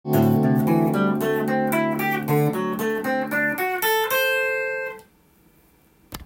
７ｔｈ　ⅡーⅤ　コード例
D7(Am7/D7)